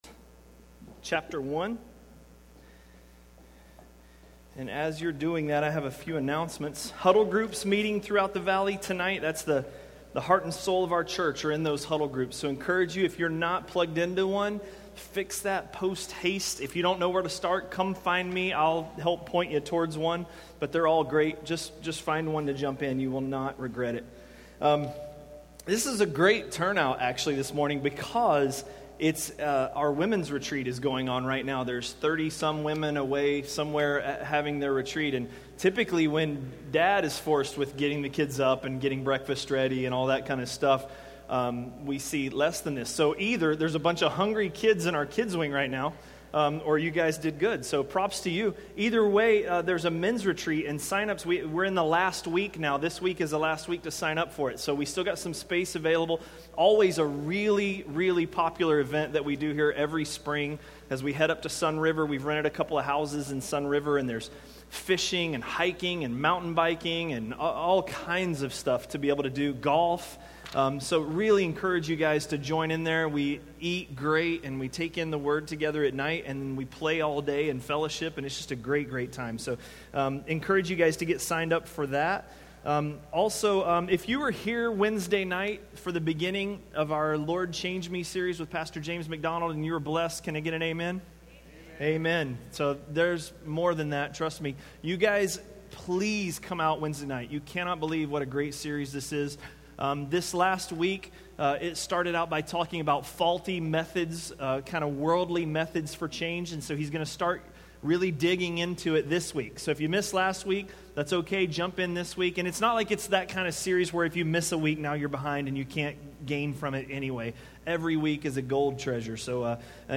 A message from the series "Romans." Romans 1:18–1:32